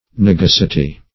Search Result for " nugacity" : The Collaborative International Dictionary of English v.0.48: Nugacity \Nu*gac"i*ty\, n. [L. nugacitas, fr. nugax, -acis, trifling.]
nugacity.mp3